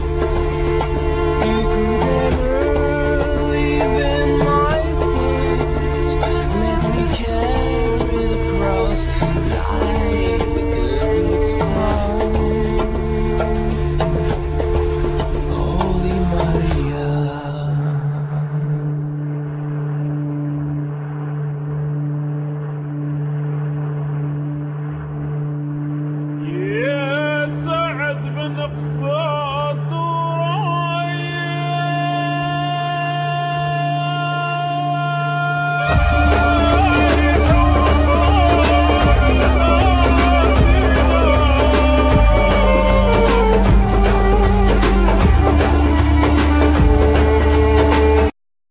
Drums,Djembe,Programming
Vocals,Keyboards,Programming
Violin
Djembe,Doundoun
Guitar